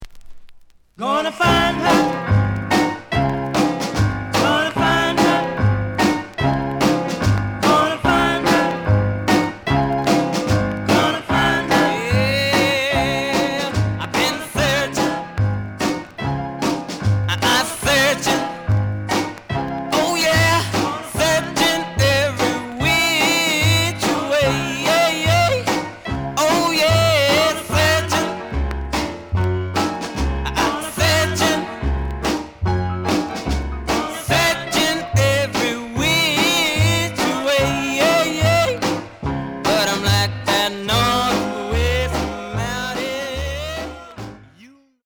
The audio sample is recorded from the actual item.
●Genre: Rhythm And Blues / Rock 'n' Roll
Some click noise on both sides due to scratches.)